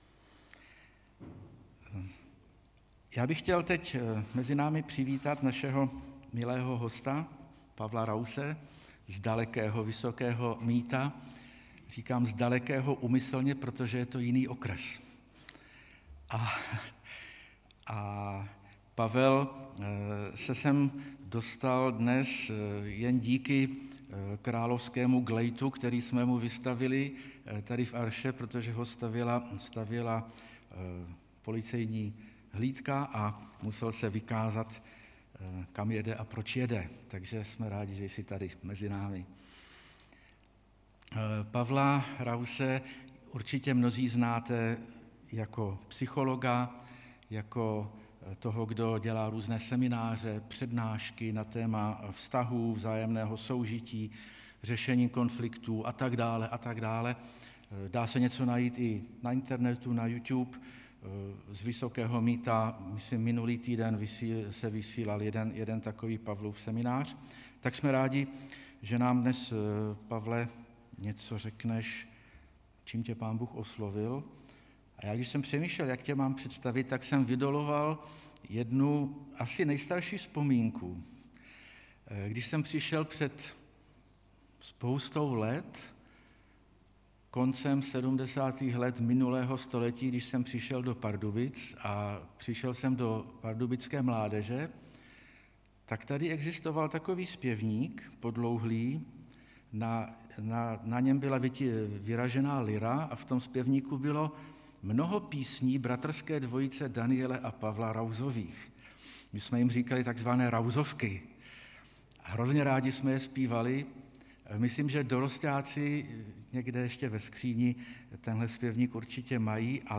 9. kázání ze série Záblesky slávy (Jan 4,27-42)
Kategorie: Nedělní bohoslužby